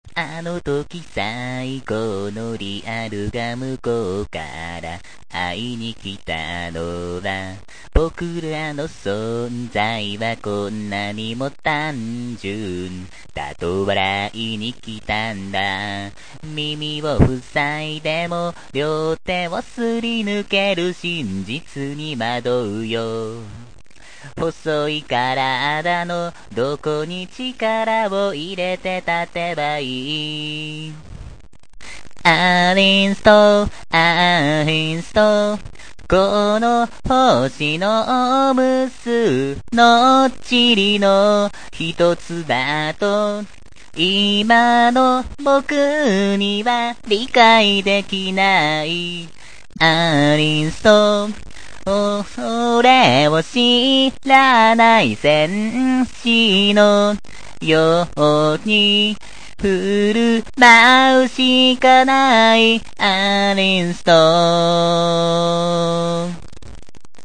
喋るとザーザー言うね、こりゃもう悲しい。
一発でいきなり歌ったので時々歌詞を忘れて止まるor声が小さくなる。
音程なんて時々外れてる。
さすがはカラオケレベル